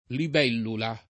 libellula [ lib $ llula ] s. f.